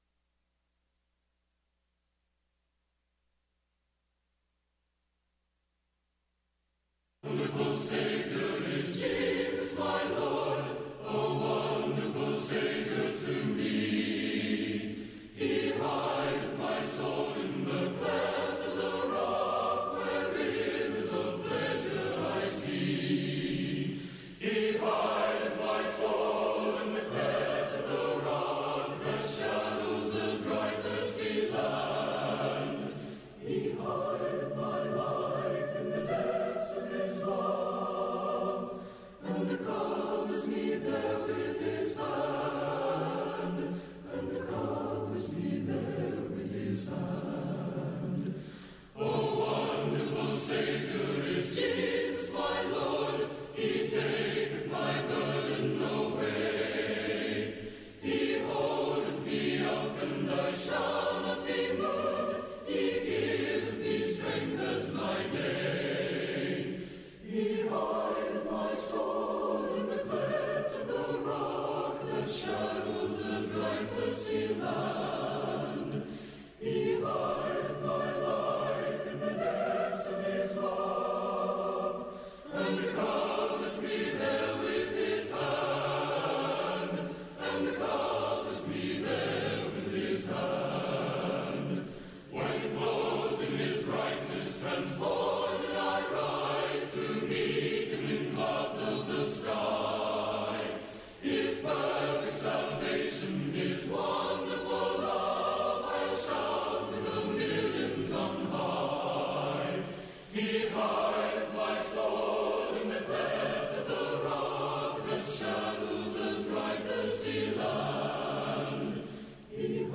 They shall share alike.” 1 Samuel 30:23-24, English Standard Version Series: Sunday PM Service